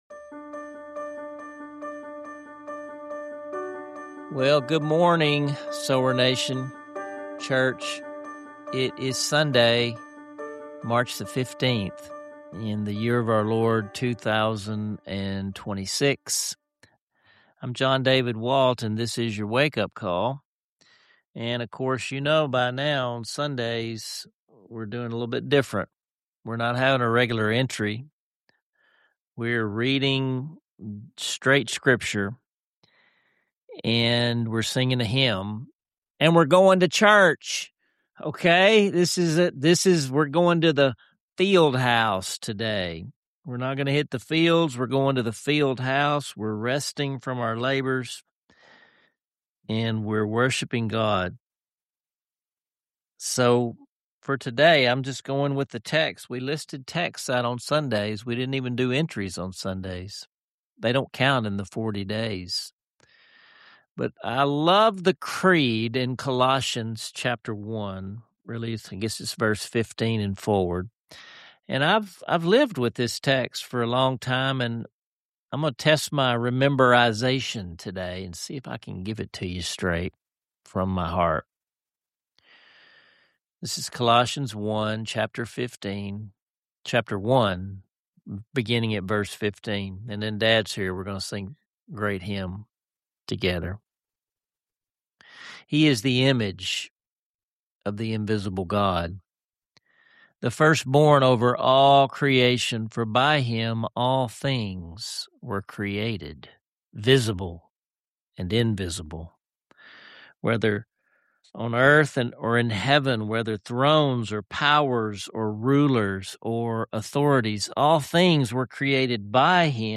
Key highlights include: A moving, memorized recitation of one of Scripture’s most exalted Christ-centered passages A powerful family hymn singalong that will have you joining in spirit, wherever you are Genuine invitations to join their church community—both in person and online—reminding every listener that there’s always a place for you You’ll feel the encouragement to step away from life’s fields and spend time worshipping with others, whether at Gillette Methodist Church, your home church, or online.